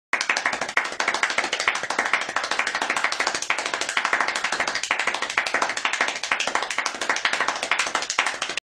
Doug Walker fast clapping - Sound Effect Button